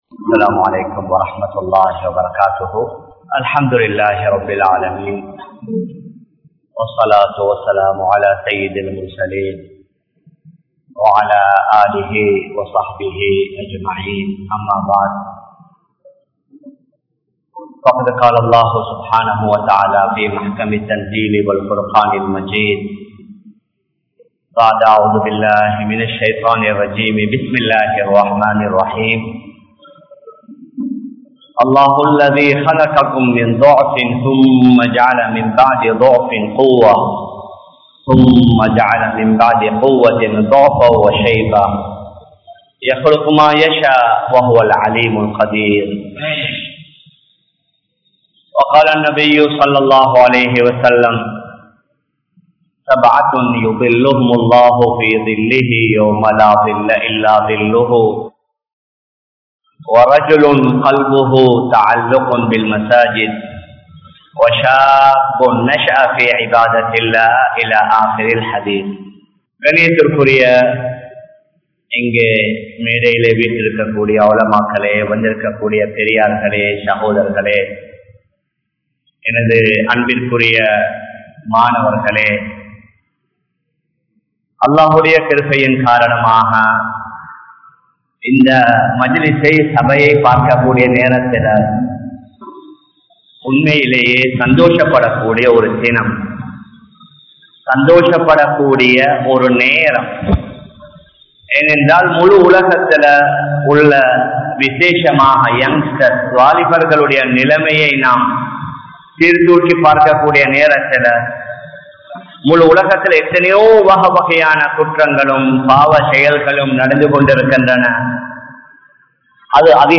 Mun Maathiriyaana Vaalifarhal (முன்மாதிரியான வாலிபர்கள்) | Audio Bayans | All Ceylon Muslim Youth Community | Addalaichenai